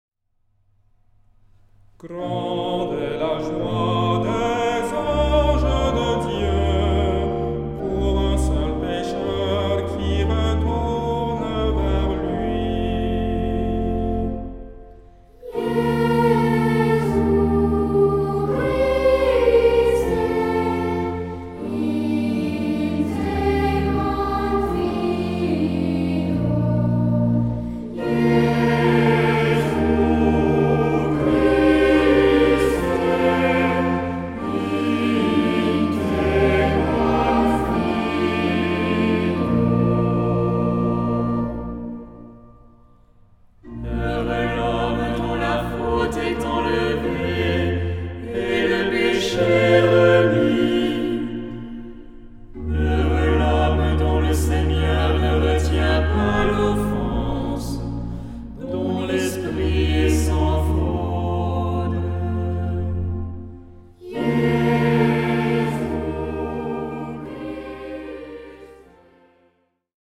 Genre-Style-Form: troparium ; Psalmody
Mood of the piece: collected
Type of Choir: SAH OR SATB  (4 mixed voices )
Instruments: Organ (1)
Tonality: B flat major